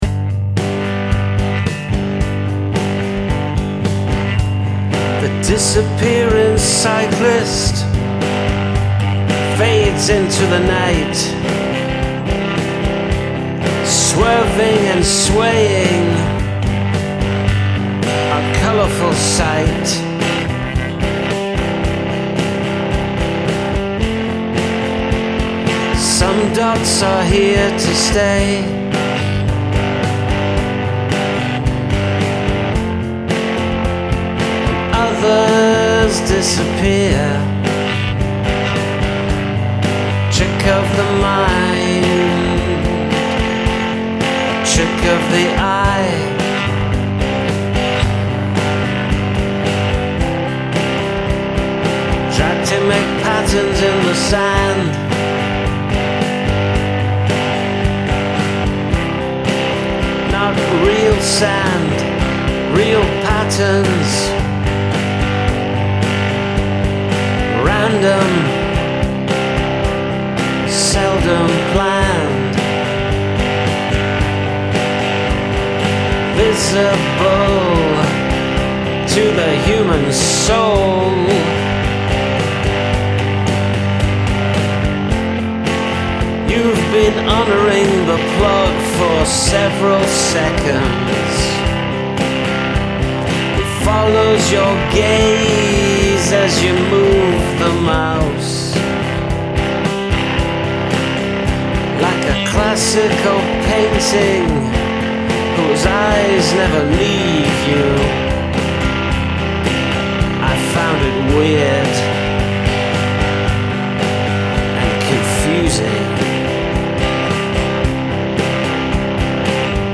I really like the guitar tone on this.